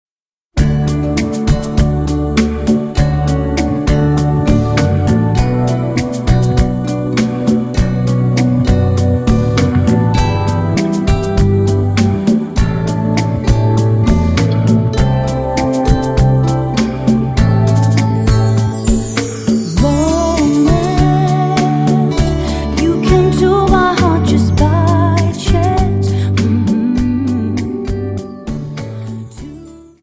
Dance: Rumba